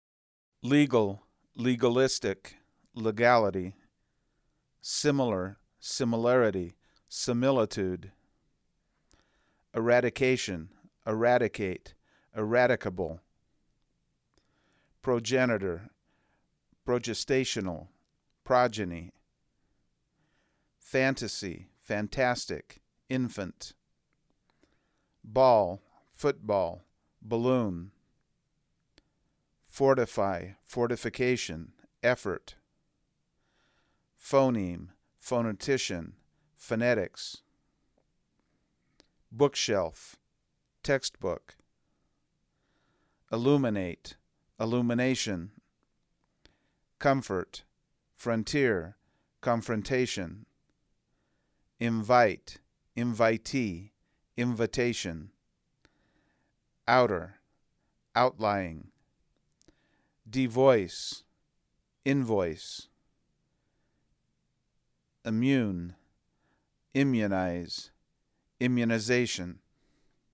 Las vocales tónicas, átonas y reducidas del inglés (el Cuadro 11.14).